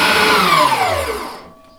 • trimmer engine power down.wav
trimmer_engine_power_down_Jji.wav